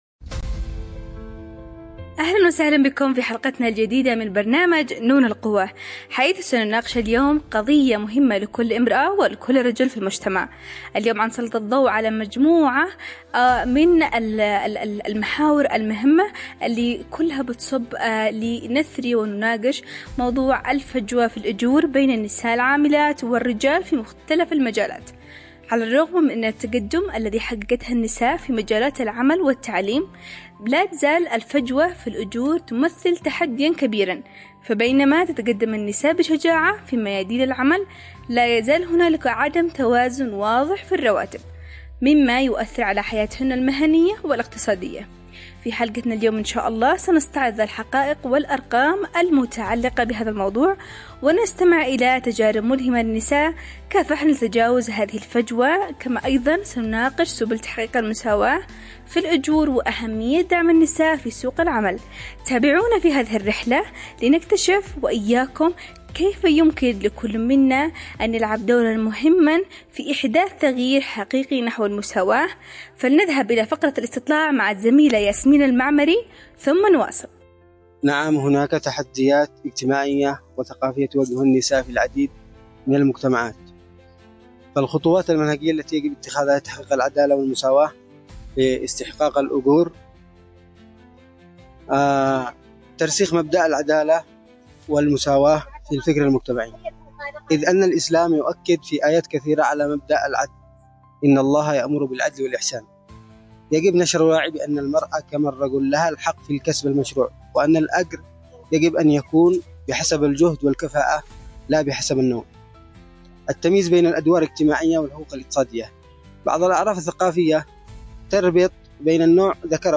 📅 الموعد: الإثنين ⏰ الساعة: 11:00 صباحًا 📻 عبر أثير إذاعة رمز ✨ حلقة مميزة تسلط الضوء على معاناة النساء القائم على النوع الاجتماعي في تحديد الرواتب.